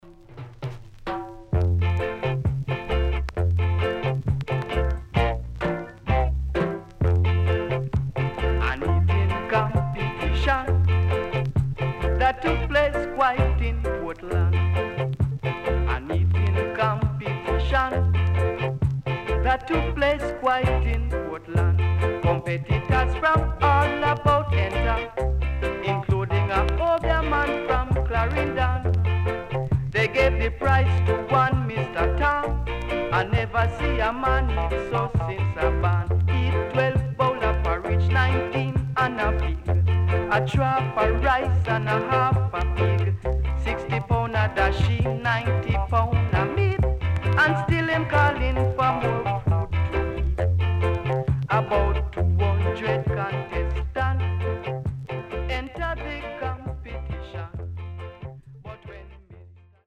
HOME > REGGAE / ROOTS
SIDE A:少しチリノイズ入ります。